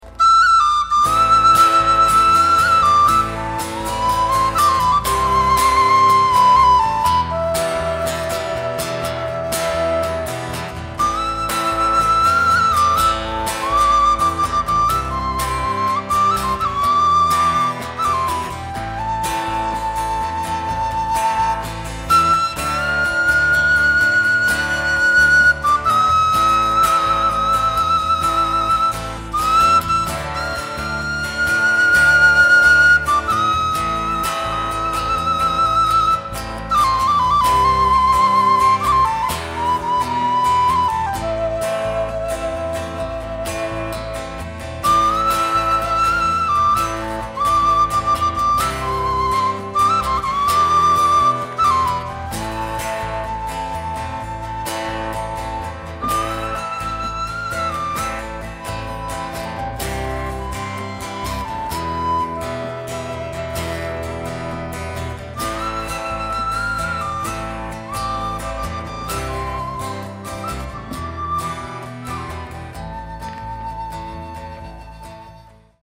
in Glasgow, Scotland in 2010
instrumental piece